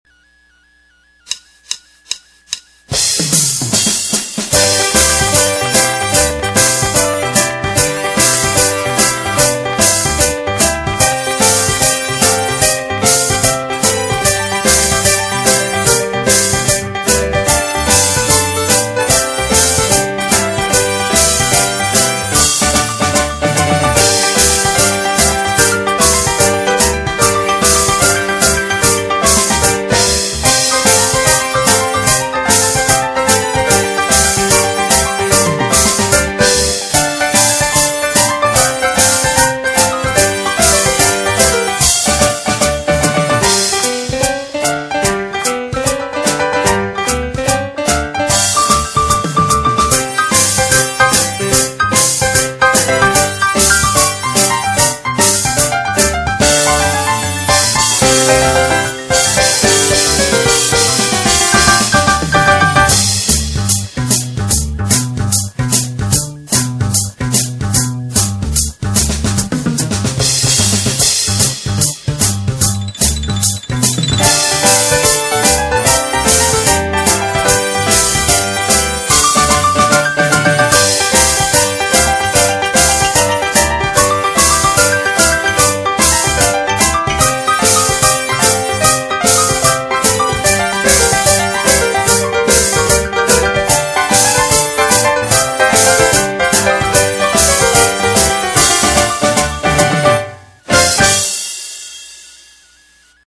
발랄한 재즈 곡입니다. 고양이가 노니는 느낌을 표현한 곡이라 하네요.